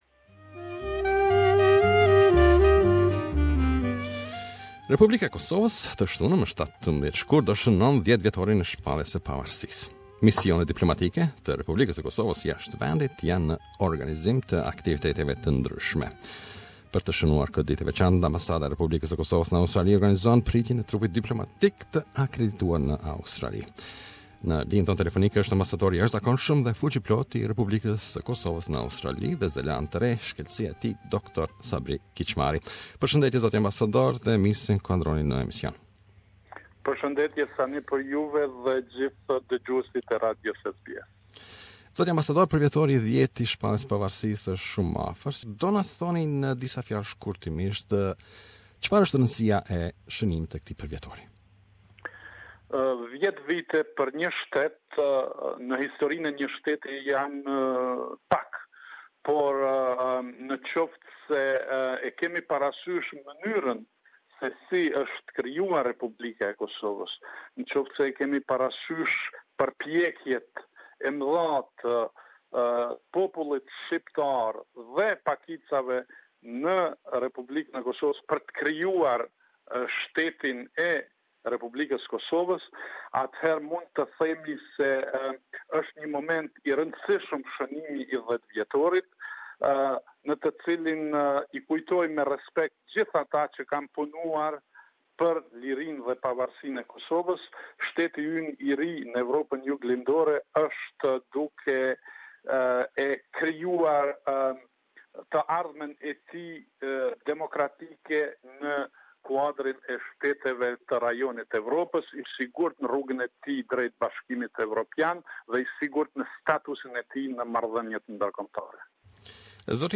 SBS Radio interviewed His Excellency the Ambassador of Republic of Kosovo in Australia Dr Sabri Kicmari.